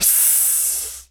snake_hiss_11.wav